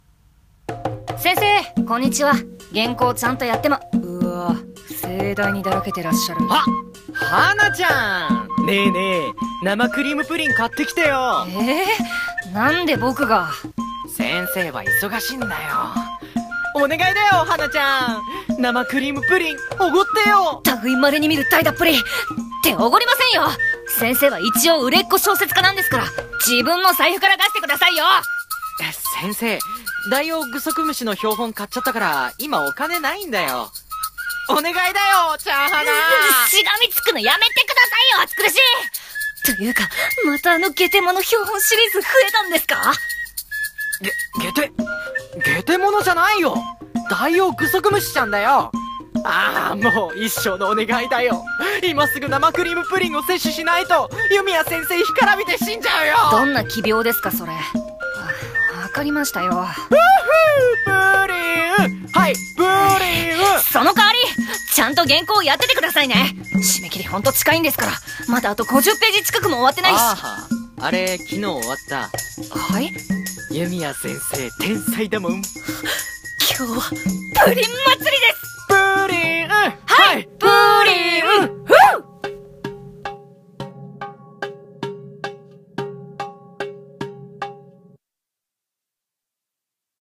【声劇】天才作家と甘味なお昼時